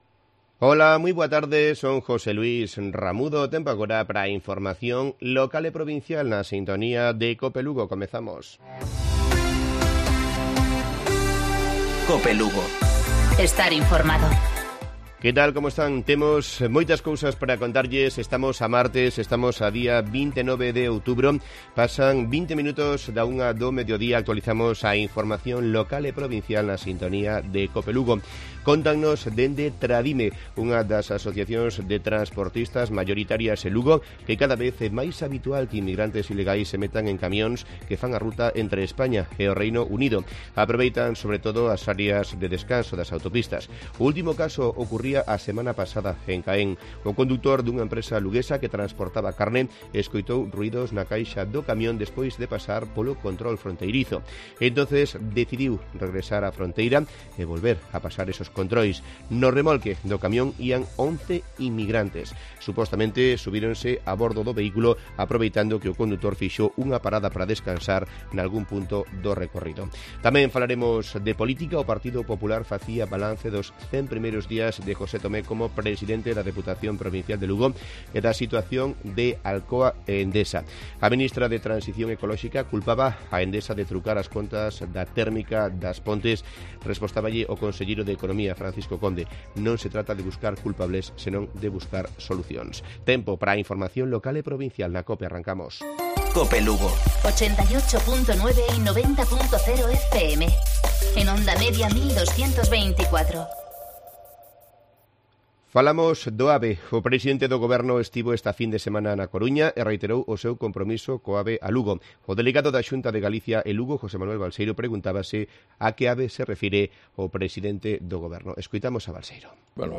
Informativo Mediodía Cope Lugo. Martes, 29 de octubre. 13:20-13:30 horas